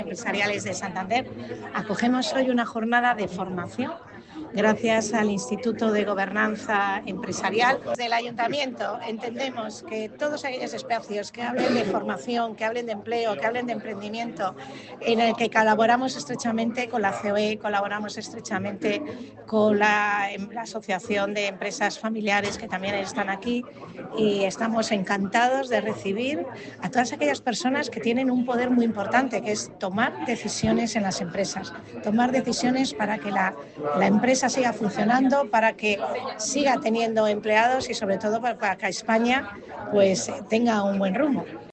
El acto se ha desarrollado en las instalaciones del Centro de Iniciativas Empresariales (CIE) del ayuntamiento de Santander que acoge estas primeras sesiones del Programa como una forma de reforzar el reciente acuerdo de colaboración entre ambas entidades (Ayuntamiento y CEOE) para fomentar la formación directiva y el emprendimiento empresarial.
Chabela Gómez-Barreda, concejala de Empleo, Emprendimiento y Desarrollo Empresarial.